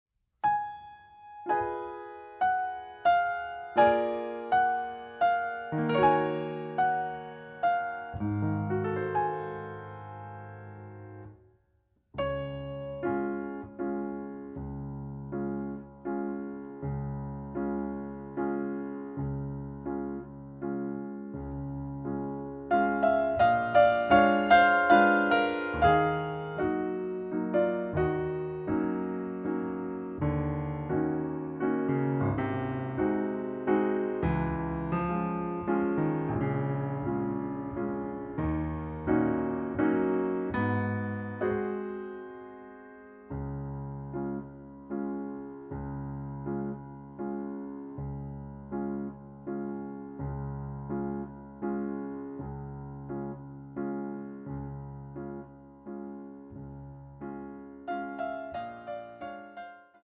accompaniment excerpt